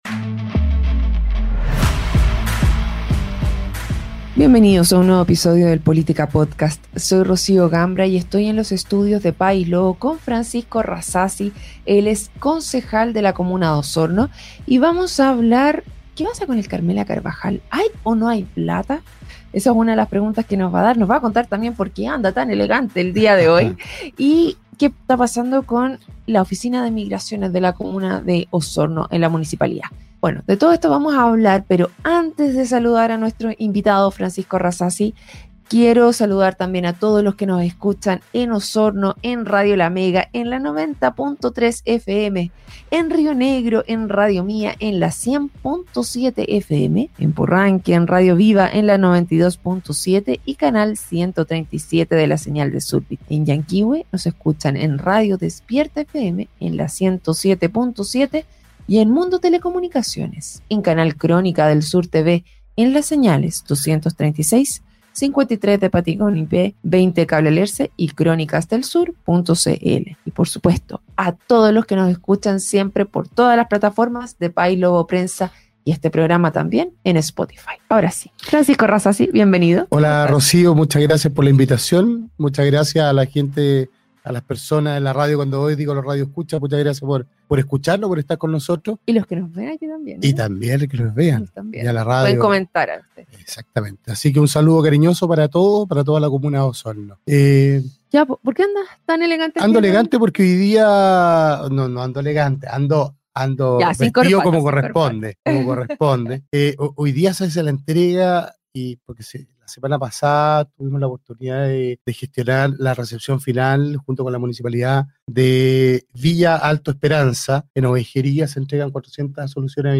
En una reciente entrevista en el programa "Política Podcast"